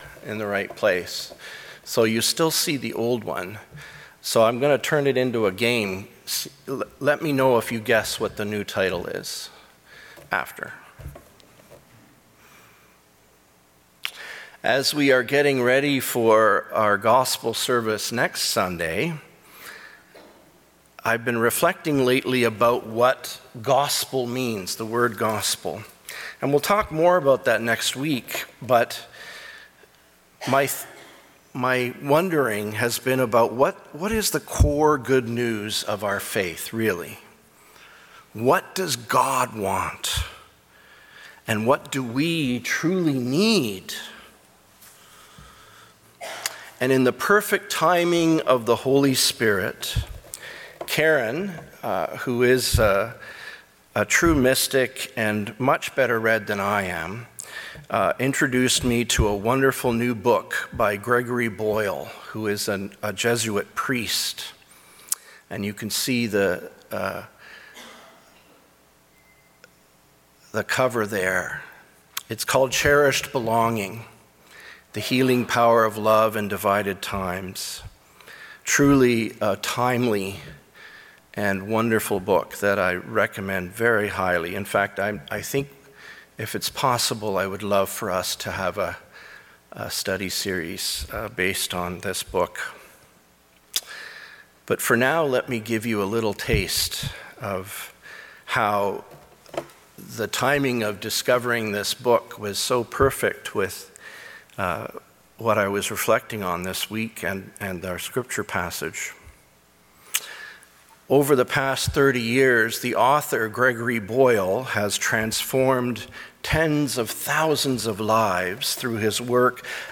Sermons | Gilmore Park United Church